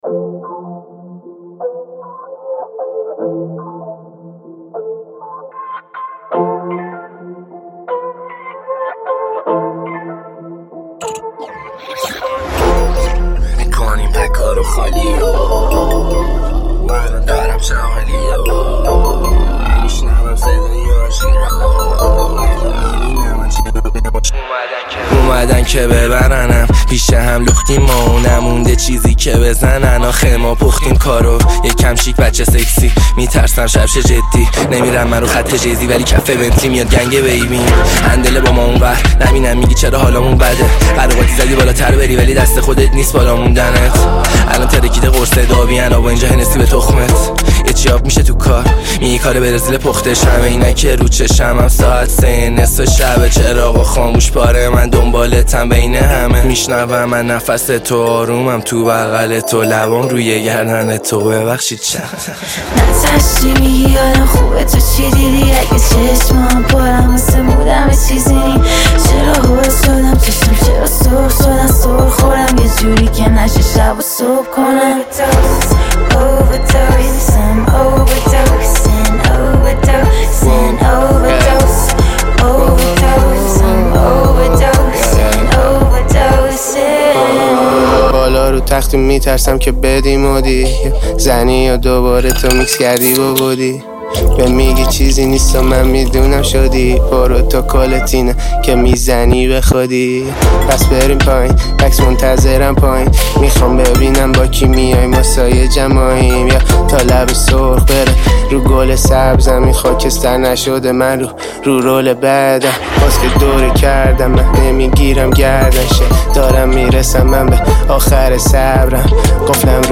آهنگ جدید های جدید رپ فارسی های جدید